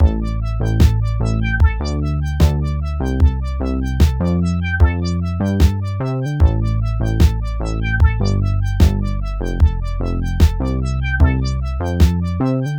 clicks.wav